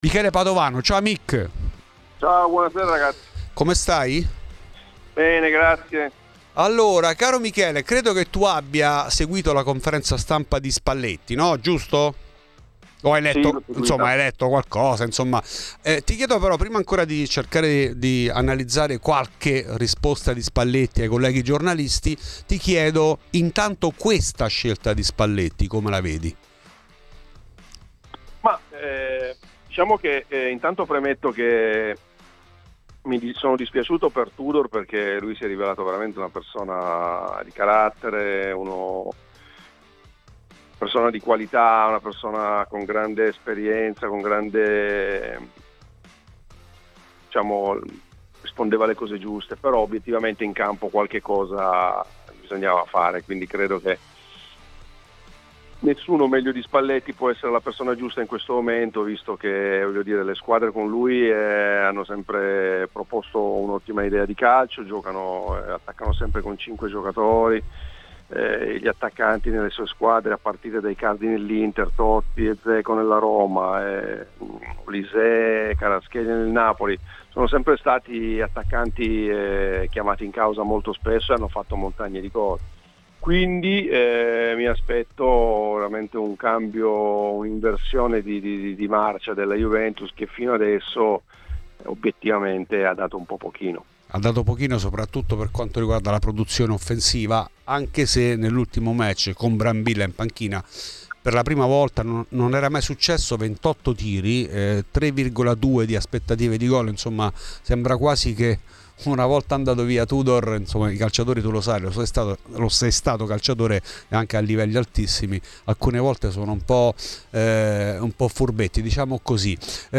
L'ex bianconero Michele Padovano è stato ospite di Fuori di Juve, trasmissione di Radio Bianconera.